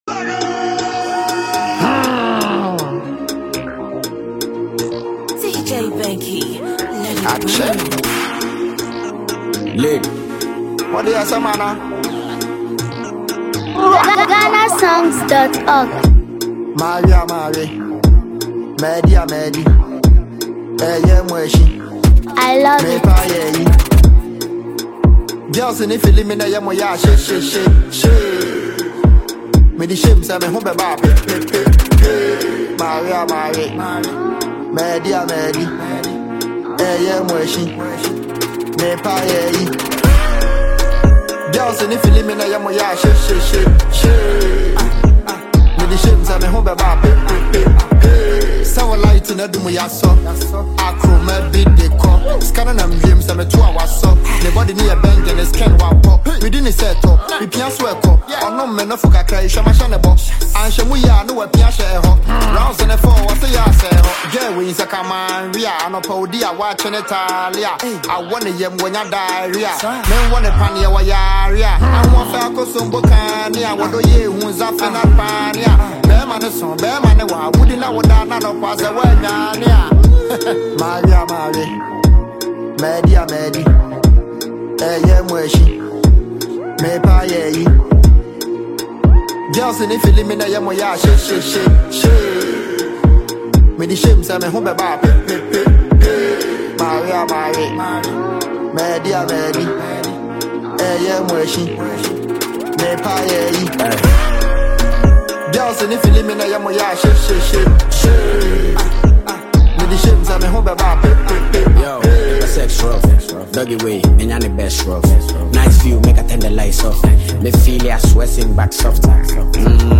Ghanaian hip-hop and Afro-rap